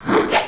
schwoop.wav